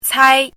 chinese-voice - 汉字语音库
cai1.mp3